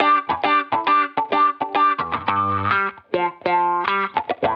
Index of /musicradar/sampled-funk-soul-samples/105bpm/Guitar
SSF_StratGuitarProc1_105G.wav